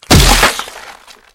crack12.mp3-2.wav